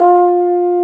Index of /90_sSampleCDs/Roland L-CD702/VOL-2/BRS_Bs.Trombones/BRS_Bs.Bone Solo